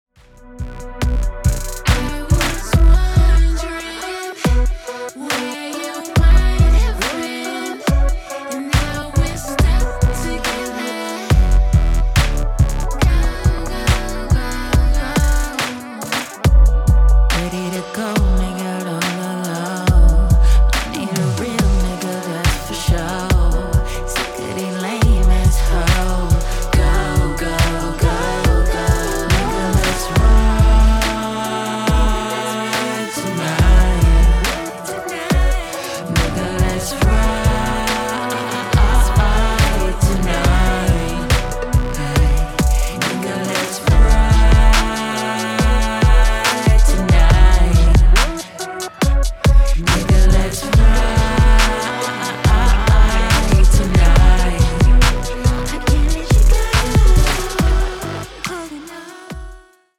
where post-modern soul meets dub lullabies and much more.